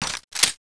p228_clipin.wav